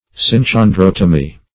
Search Result for " synchondrotomy" : The Collaborative International Dictionary of English v.0.48: Synchondrotomy \Syn`chon*drot"o*my\, n. [Gr.